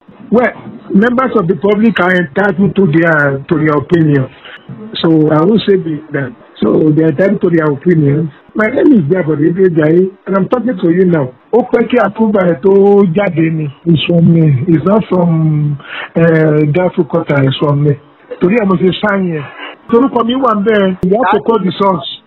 Interview Audio Below: